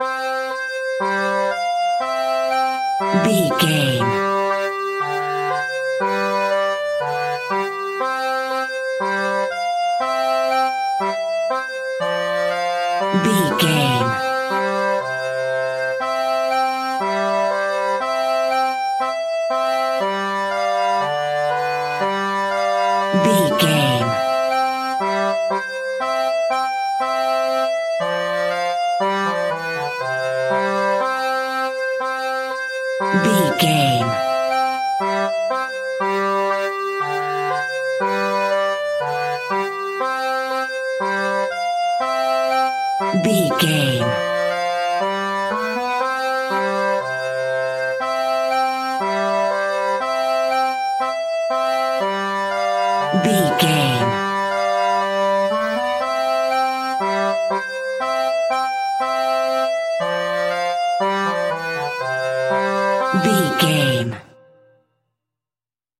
Ionian/Major
nursery rhymes
childrens music